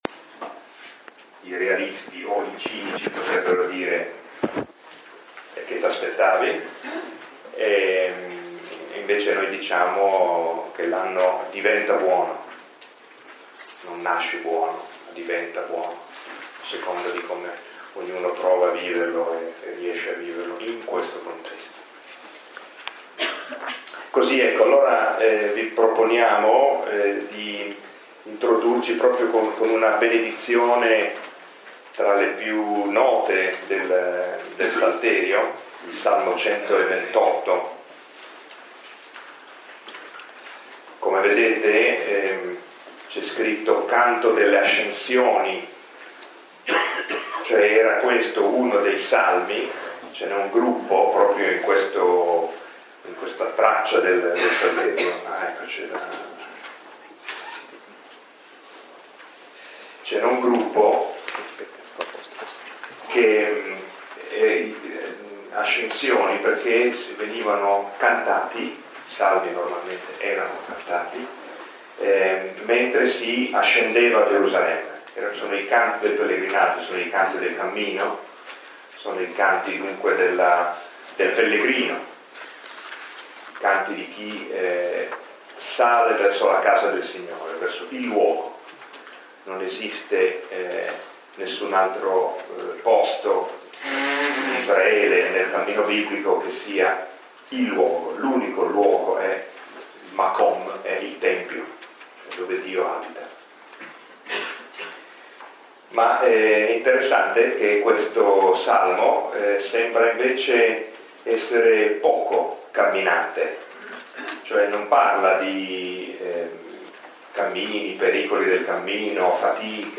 Lectio 4 – 18 gennaio 2015 – Antonianum – Padova